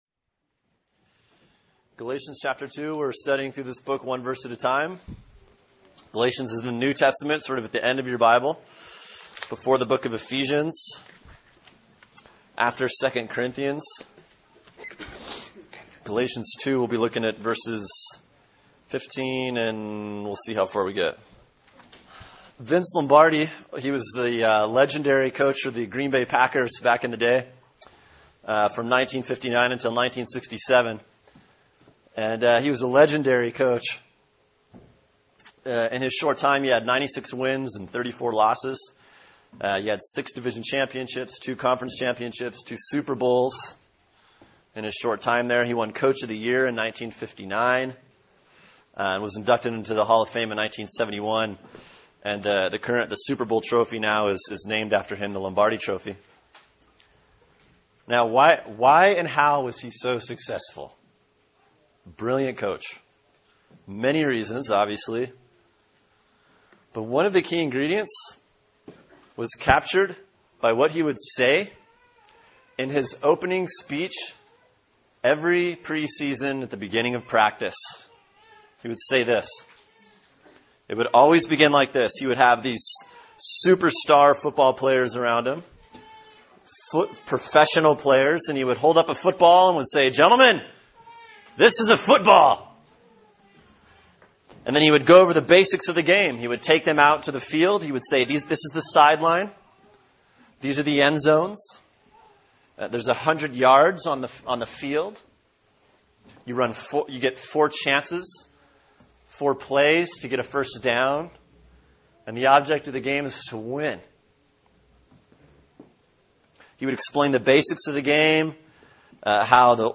Sermon: Galatians 2:15-21 “The Heart of the Gospel” | Cornerstone Church - Jackson Hole